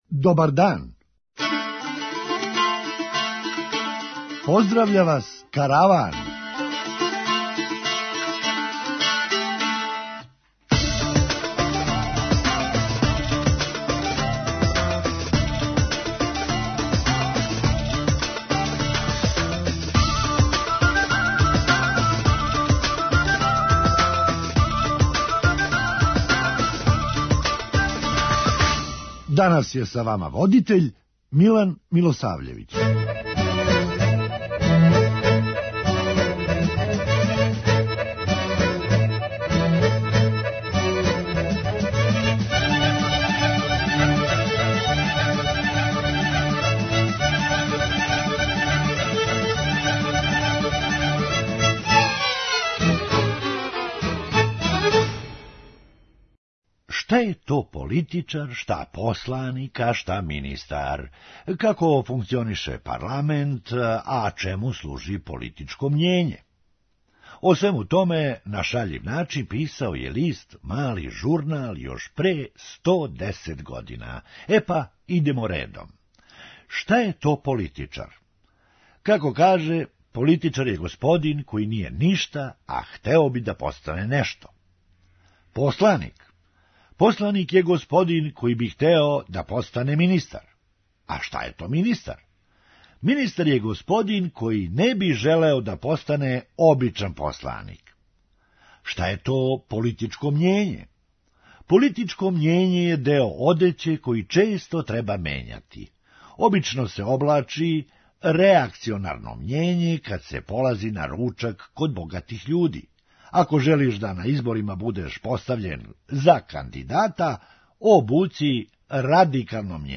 Хумористичка емисија
Море, док обиђеш сва купатила, прође годишњи одмор. преузми : 8.94 MB Караван Autor: Забавна редакција Радио Бeограда 1 Караван се креће ка својој дестинацији већ више од 50 година, увек добро натоварен актуелним хумором и изворним народним песмама.